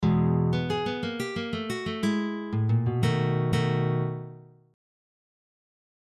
Blues Exercises > Turnaround - 3